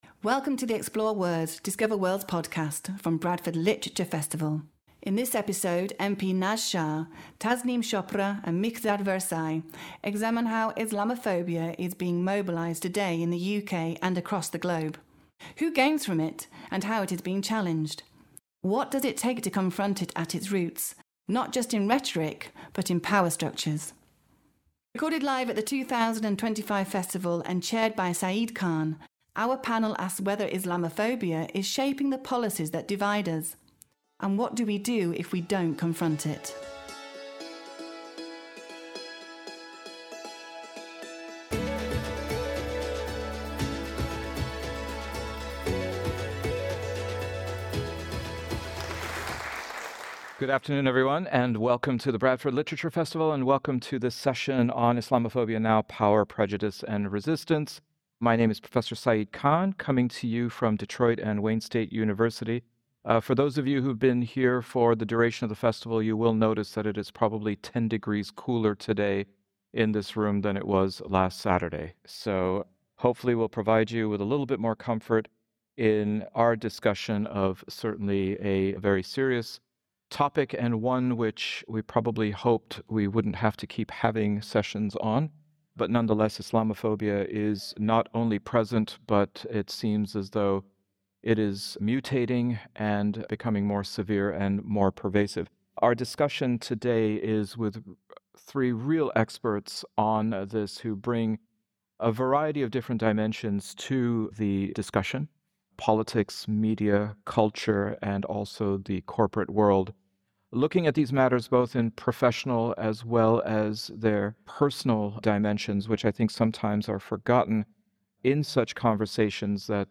From counterterrorism policy and surveillance to media narratives and foreign wars, Muslims are routinely positioned as threats. This panel examines how Islamophobia is being mobilised today in the UK and across the globe. Who gains from it, and how is it being challenged?